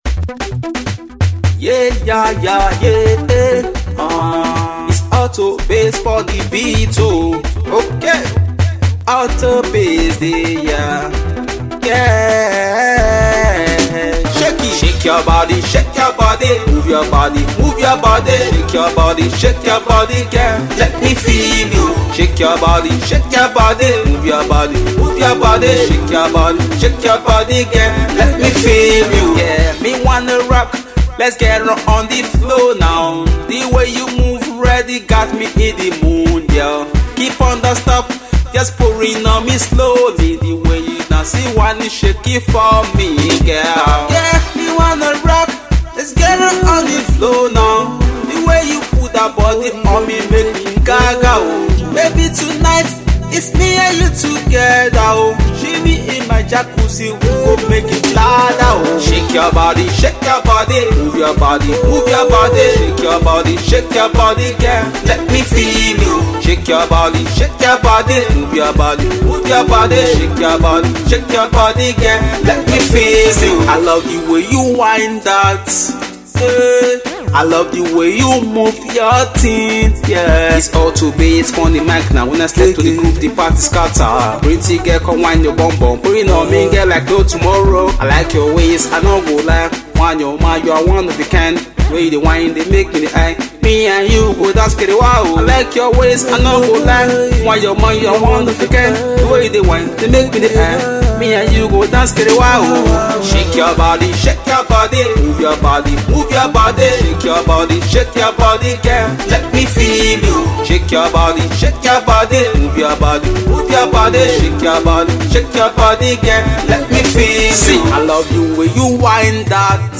Afro
with an energetic move.